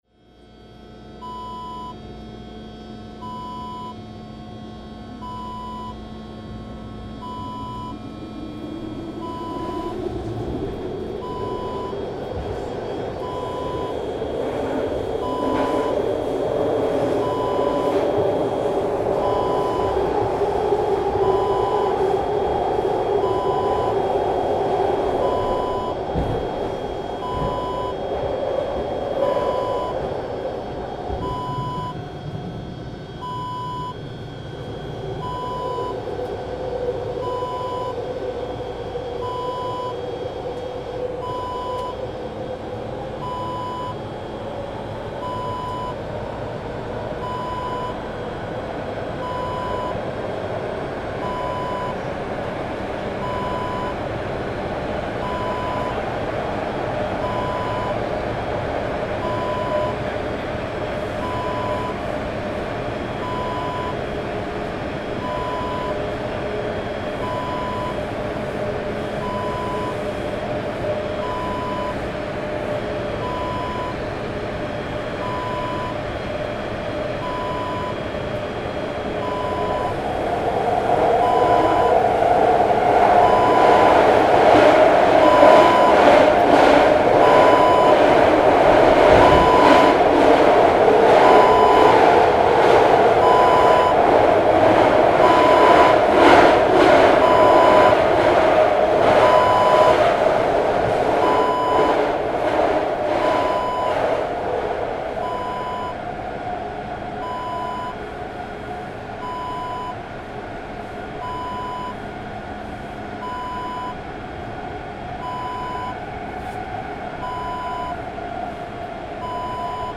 Subway train ambience sound effect .wav #2
Description: Subway interior background ambience. Subway starts moving and then moves at constant speed.
Properties: 48.000 kHz 24-bit Stereo
A beep sound is embedded in the audio preview file but it is not present in the high resolution downloadable wav file.
Keywords: subway, tube, underground, metro, train, moving, interior, inside, background, noise, ambience, on-board, ride, riding, depart, departing
subway-train-ambience-preview-02.mp3